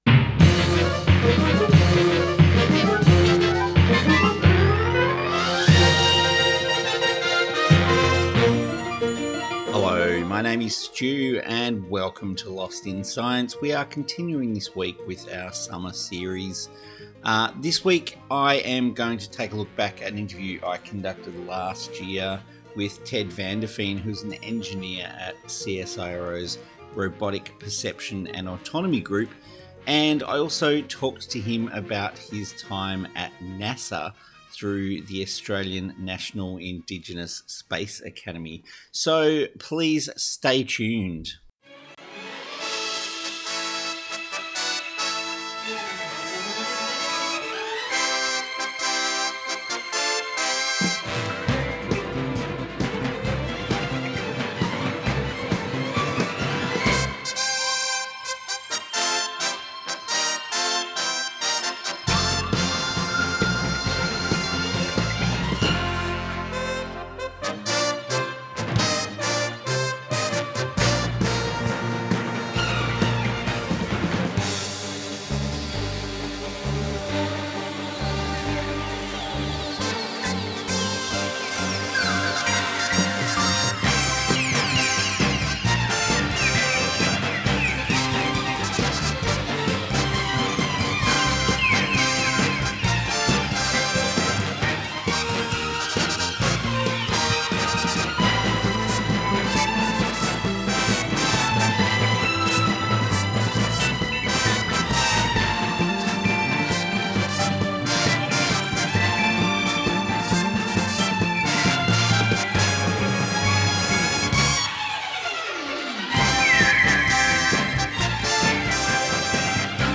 Our summer series continues this week with an interview